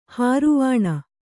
♪ hāruvāṇa